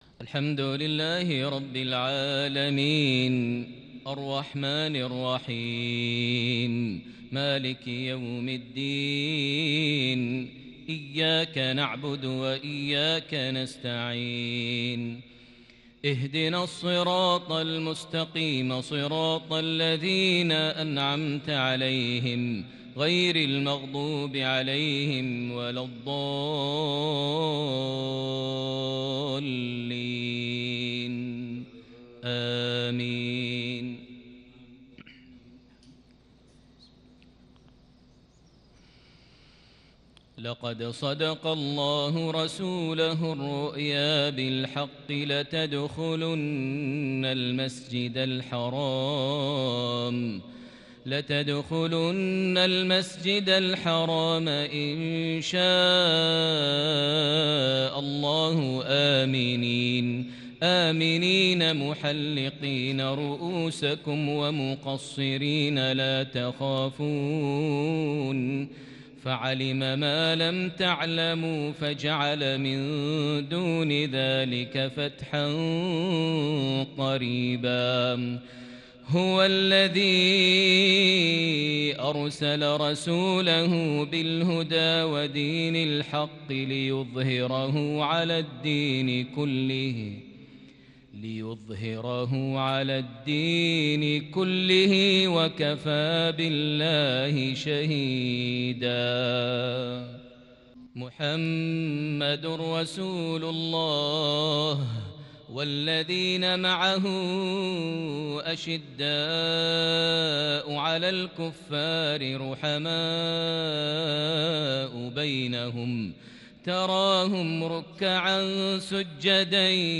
تلاوة فريدة بالكرد لخواتيم سورة الفتح | مغرب 2 ذو الحجة 1441هـ > 1441 هـ > الفروض - تلاوات ماهر المعيقلي